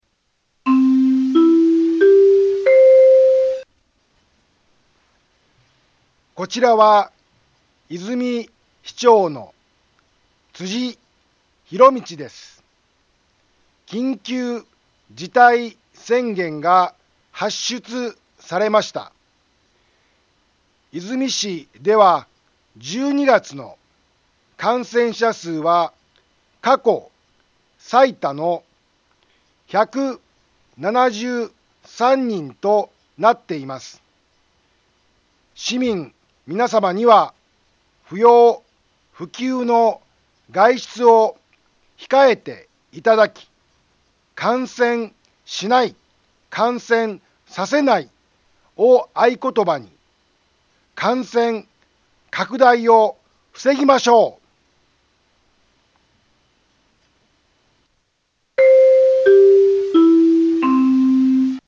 BO-SAI navi Back Home 災害情報 音声放送 再生 災害情報 カテゴリ：通常放送 住所：大阪府和泉市府中町２丁目７−５ インフォメーション：こちらは、和泉市長の辻 ひろみちです。 緊急事態宣言が発出されました。 和泉市では１２月の感染者数は過去最多の１７３人となっています。